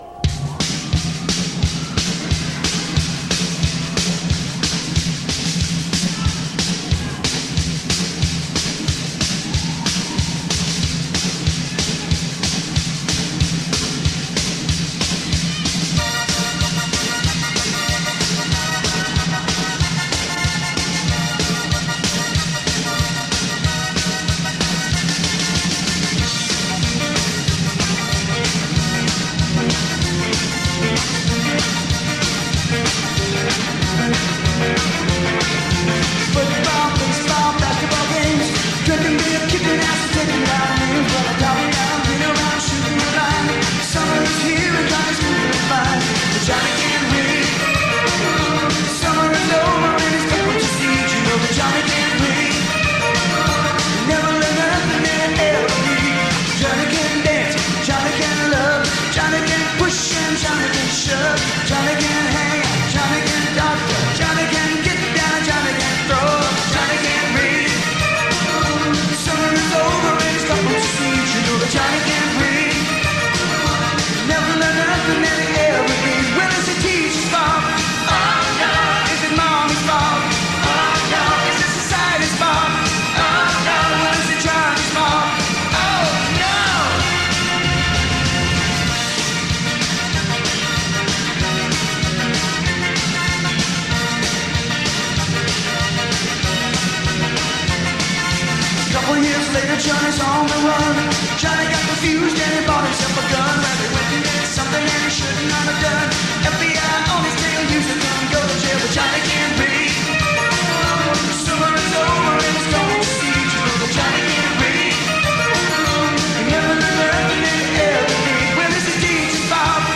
Live Audio Downloads
Saratoga 1985